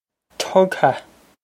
Tugtha Tug-ha
This is an approximate phonetic pronunciation of the phrase.